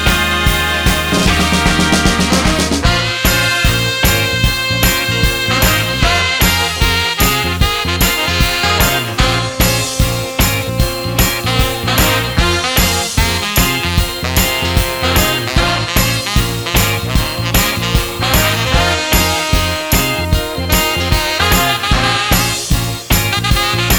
Ska